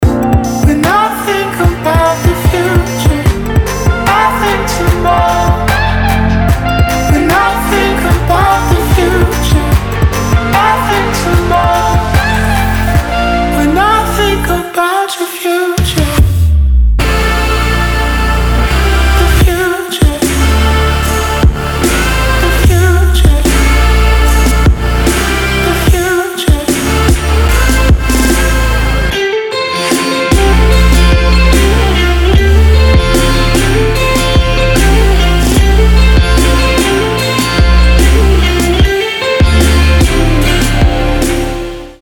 Дабстеп